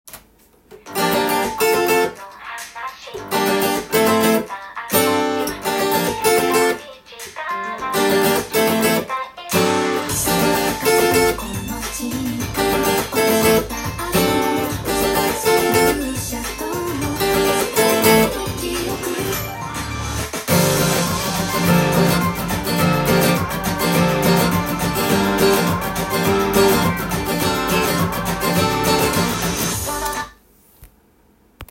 オリジナルギターストロークtab譜
音源にあわせてアコギで譜面通り弾いてみました
アコースティックギターで弾いてみると摩訶不思議な雰囲気がする
最初から１６分休符という細かい休符が使われています。
今回は右手で触り休符を作って独特のリズムを作ってみました。
ノンダイアトニックコードと言われる通常の曲では使わないコードが使われています。
ストロークになります。